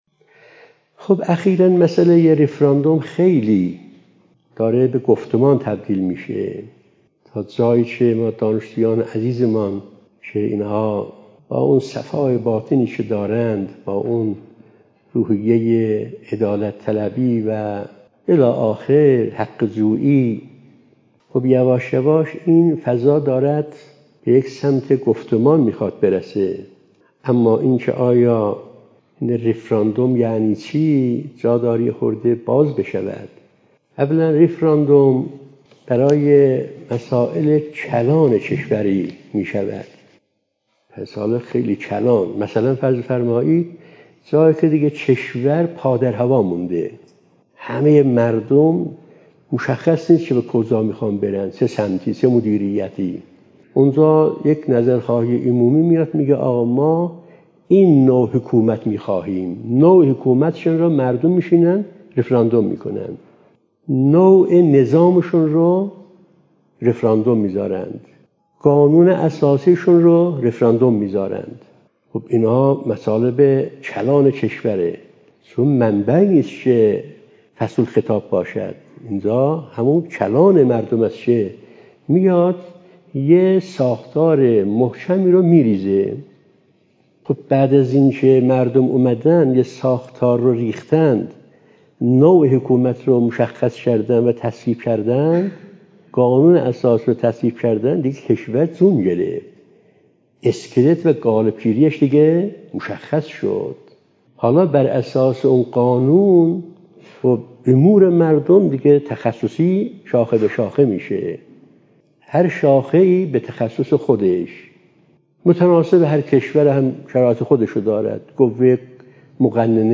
از جلسه اولین جمعه ماه قمری| ماه شوال 🎙حکومت اسلامی| جایگاه رفراندوم در حکومت و سوءاستفاده مغرضین از آن